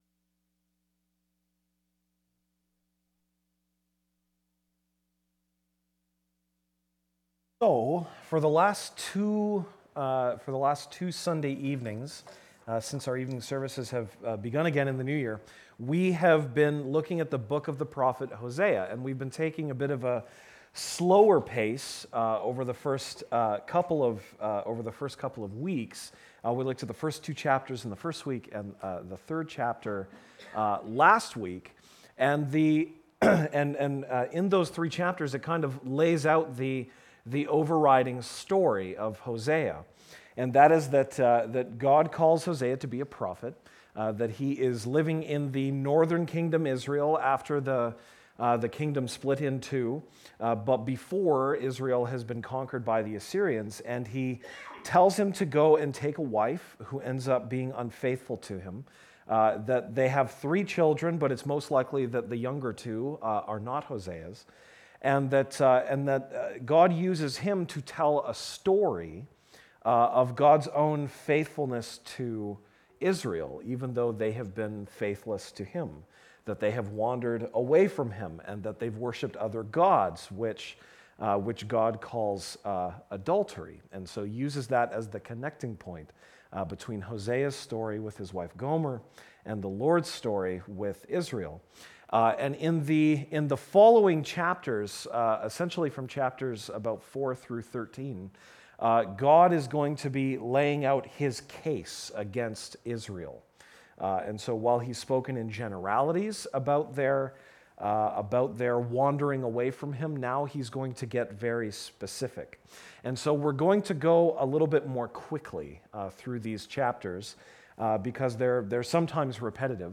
Series: Archived Sermons
January 24, 2016 (Sunday Evening)